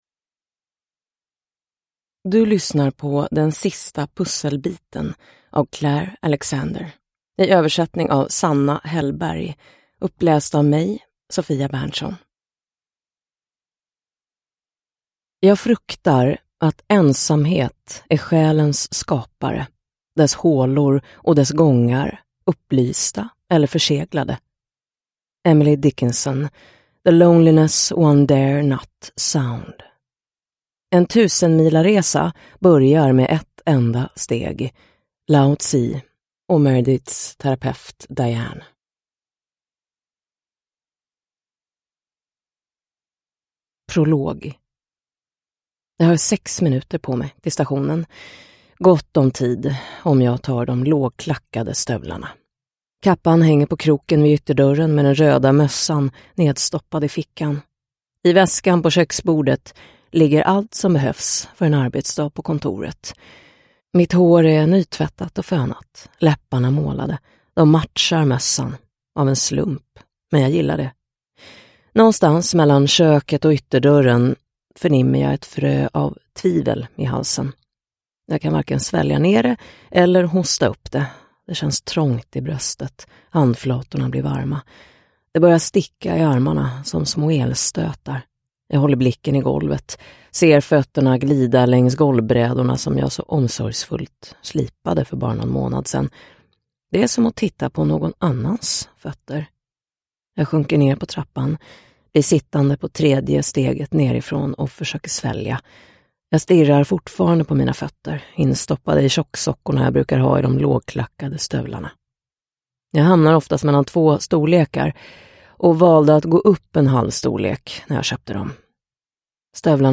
Den sista pusselbiten – Ljudbok – Laddas ner